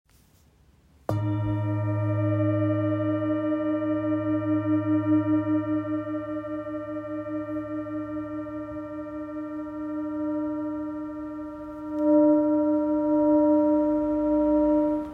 GSB Singing Bowl 23.5cm - 29.5cm
Light in weight yet remarkably strong in sound, this bowl produces deep, grounding vibrations that can be both felt and heard.